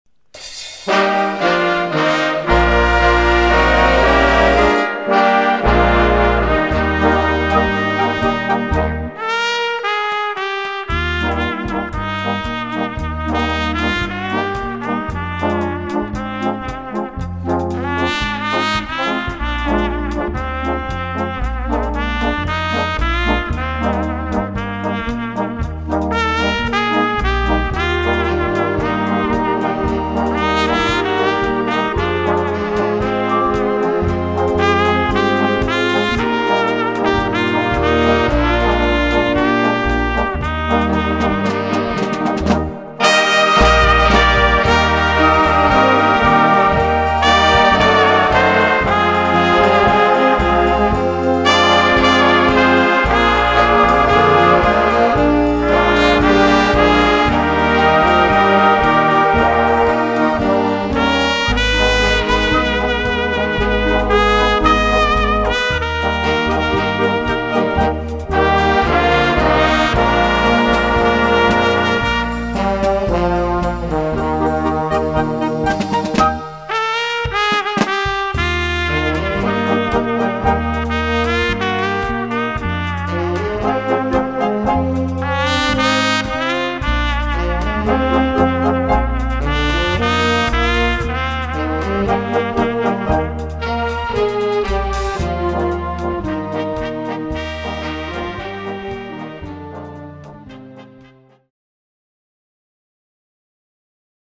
Solo Trompete Schwierigkeit
Blasorchester Zu hören auf